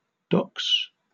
Ääntäminen
Ääntäminen UK Tuntematon aksentti: IPA : /dɒks/ Haettu sana löytyi näillä lähdekielillä: englanti Kieli Käännökset suomi telakka Docks on sanan dock monikko.